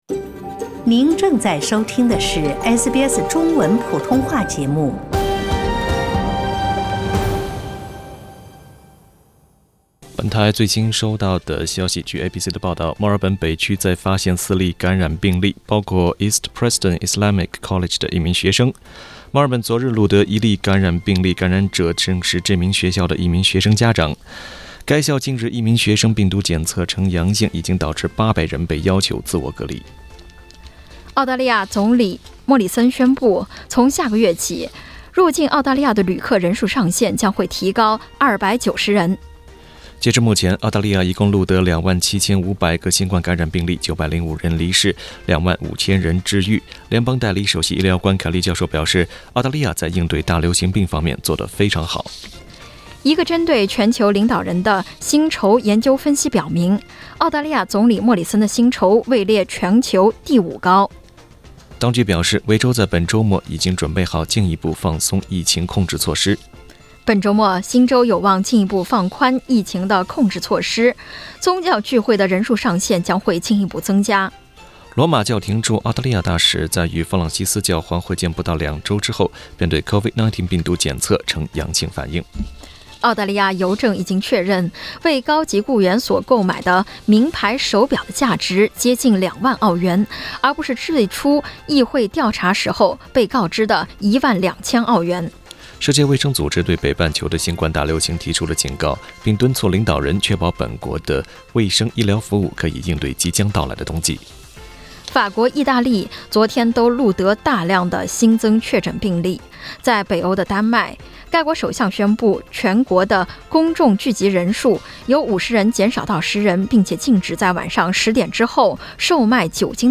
SBS早新闻（10月24日）